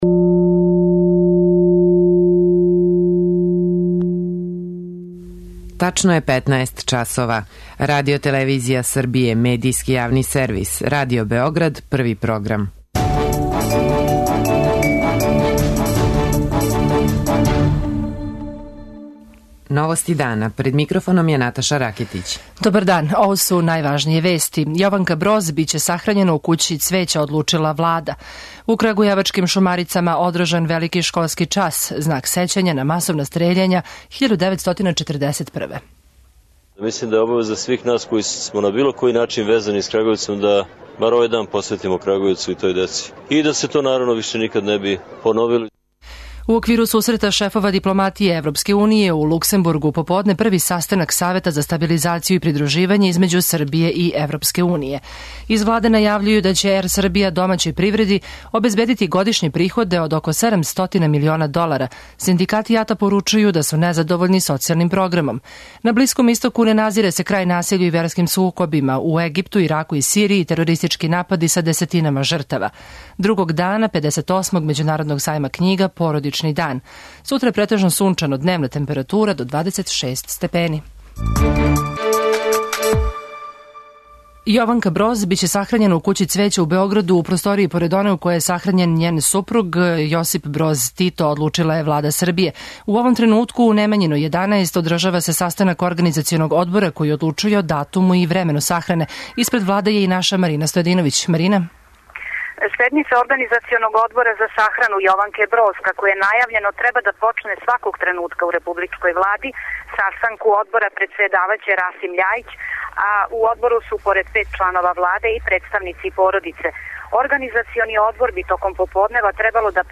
Новости дана